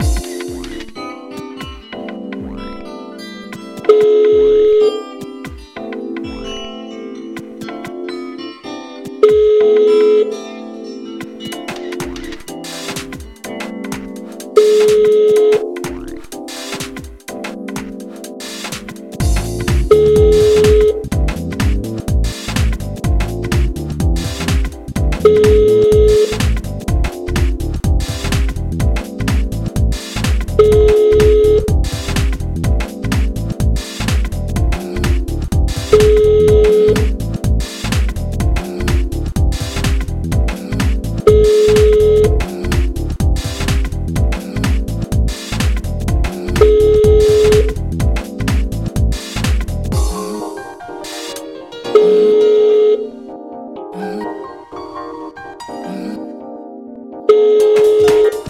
telephone_jazz.mp3